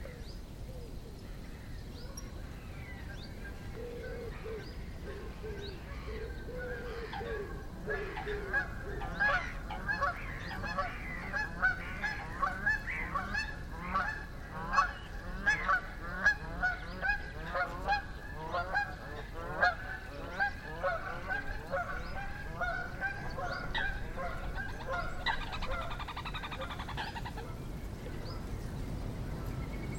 Bernache du Canada - Mes zoazos
bernache-canada.mp3